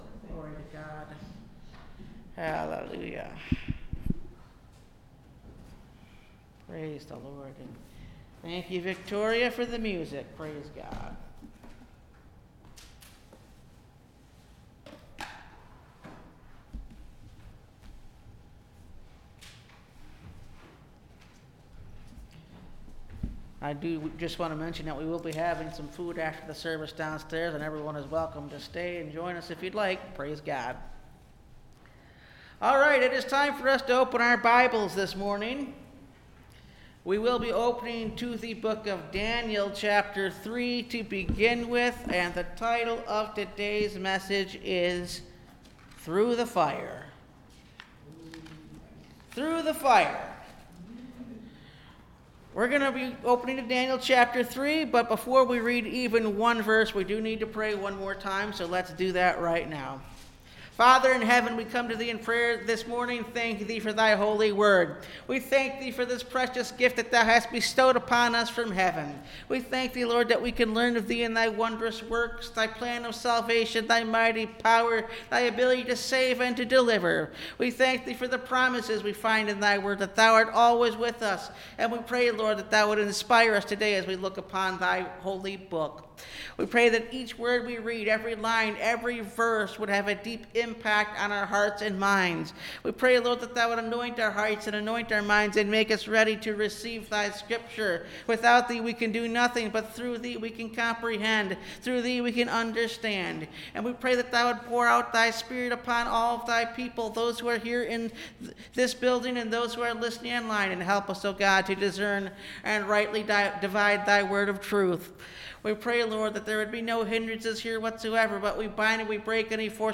Through The Fire (Message Audio) – Last Trumpet Ministries – Truth Tabernacle – Sermon Library